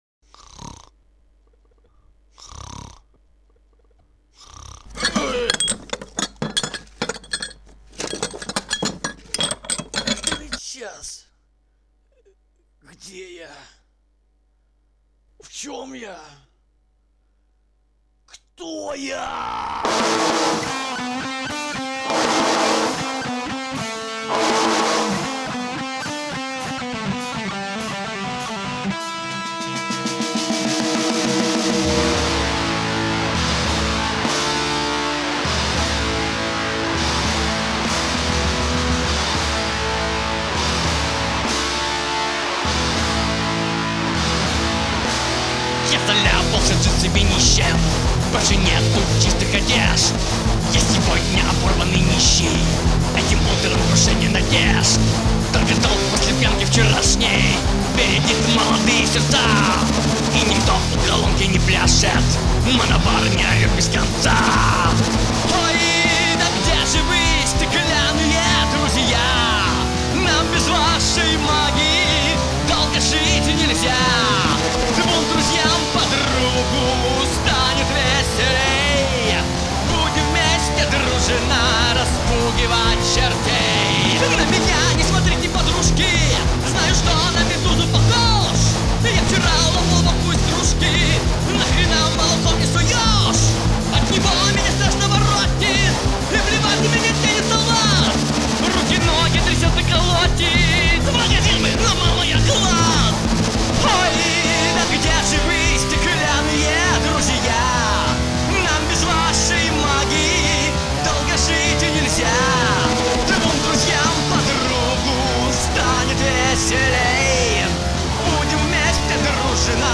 Первый мы записали на еле живом оборудовании, но играли люди (кроме ударных, они прописаны в синте, не было тогда еще установки).